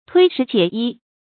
推食解衣 注音： ㄊㄨㄟ ㄕㄧˊ ㄐㄧㄝ ˇ ㄧ 讀音讀法： 意思解釋： 推：讓。